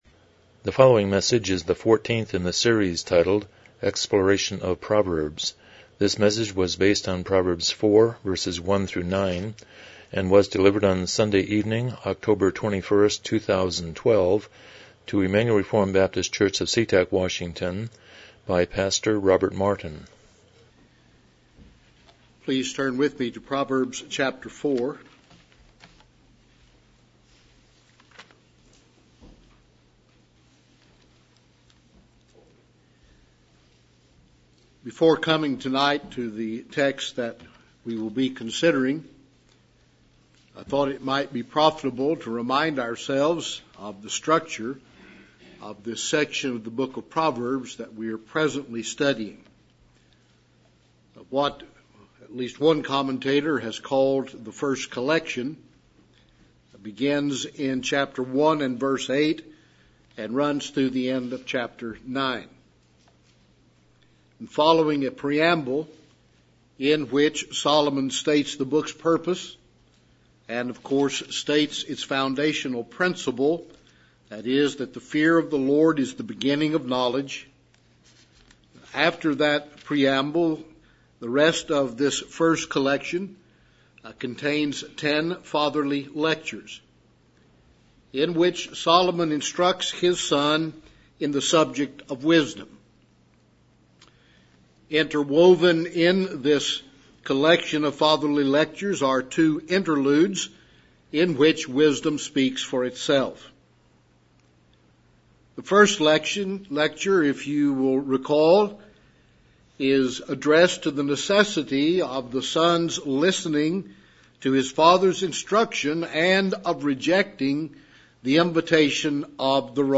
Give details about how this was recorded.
Service Type: Evening Worship